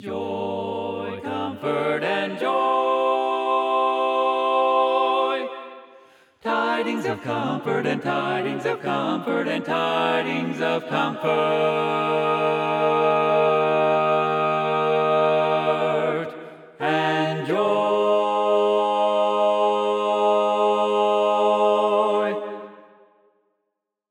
Key written in: G Minor
Type: Barbershop